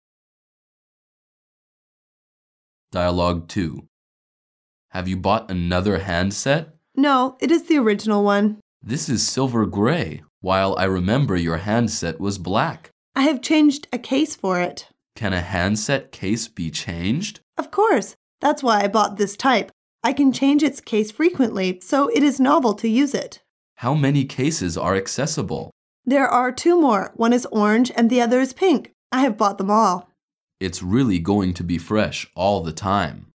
Dialoug 2